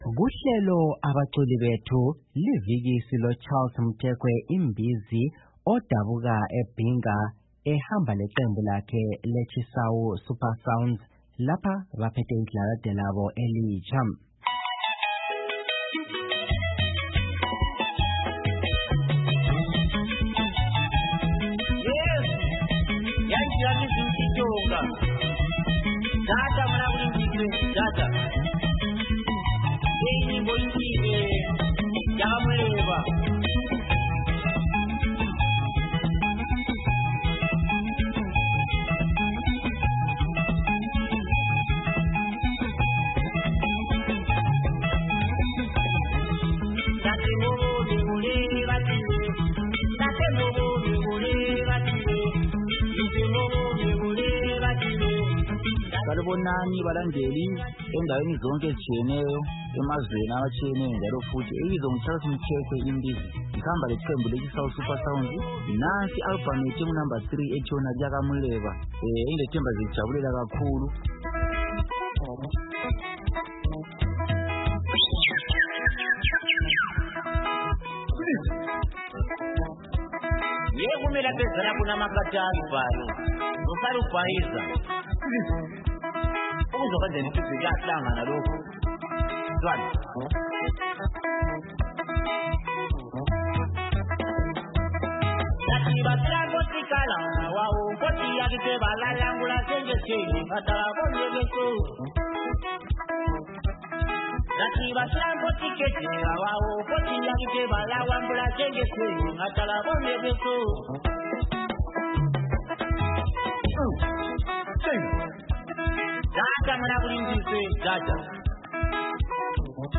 silomculi we-rhumba